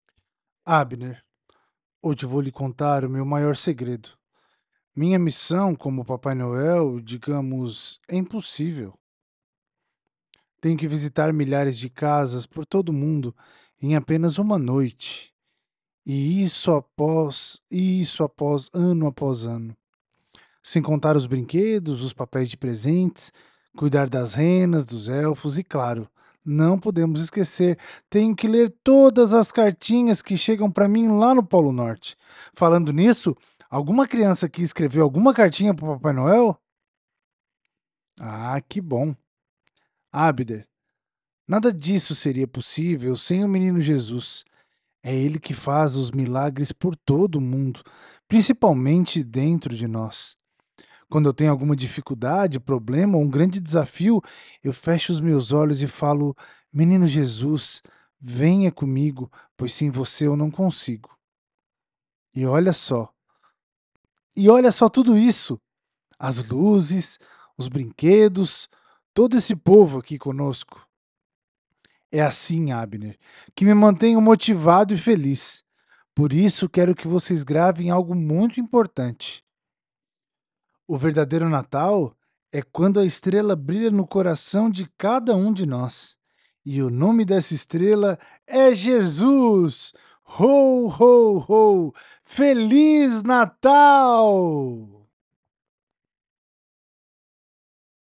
Voz de papai Noel para teatro